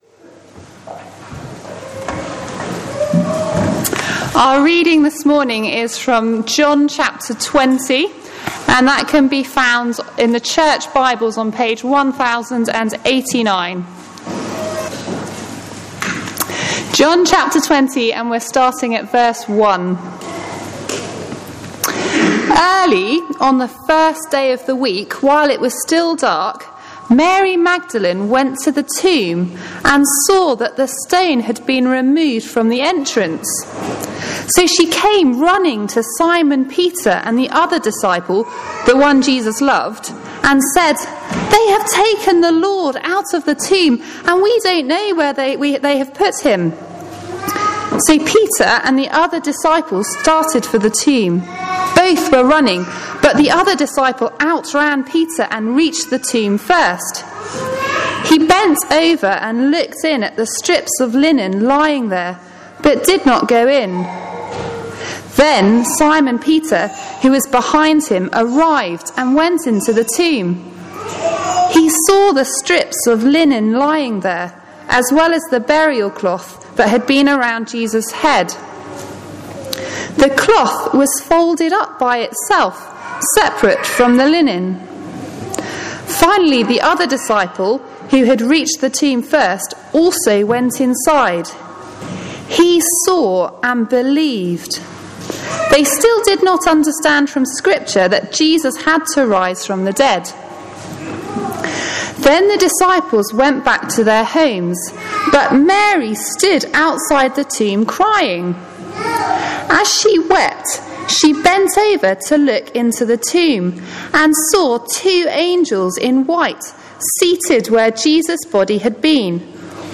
John 20 – Easter Morning Service